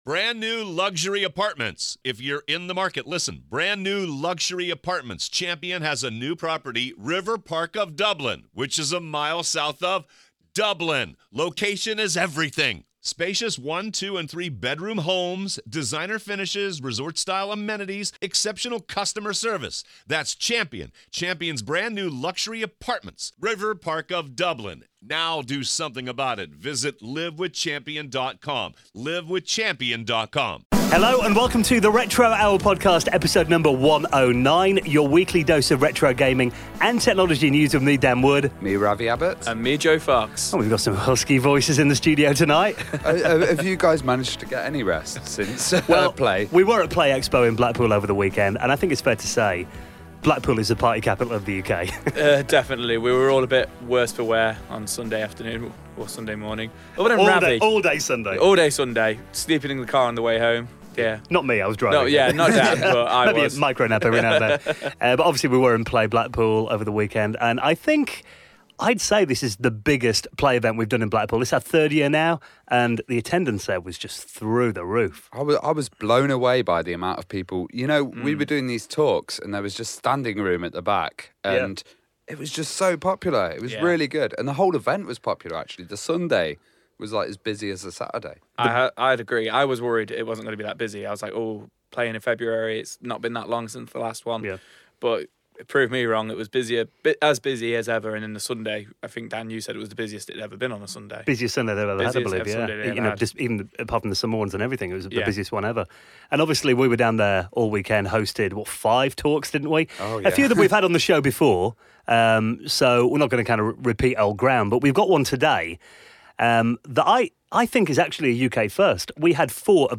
Retro Gaming Youtubers Panel from PLAY Expo Blackpool - The Retro Hour EP109